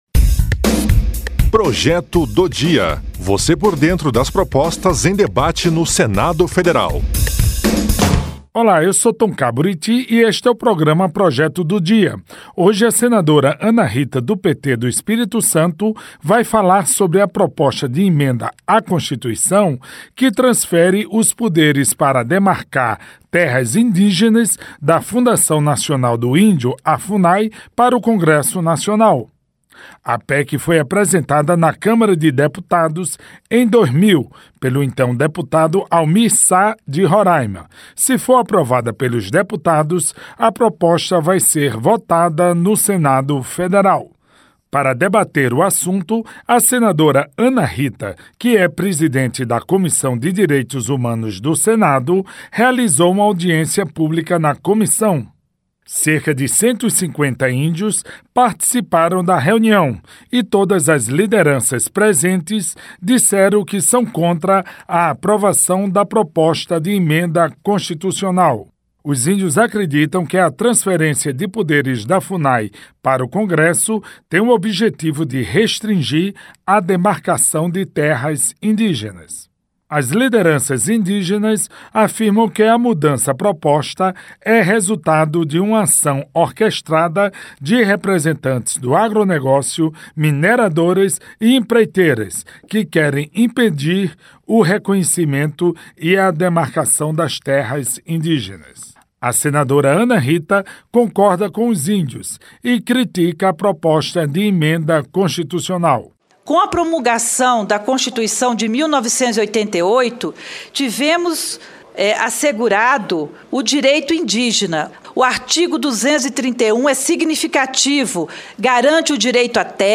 Entrevista com a senadora Ana Rita (PT-ES).